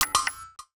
UIClick_Double Click Metallic 02.wav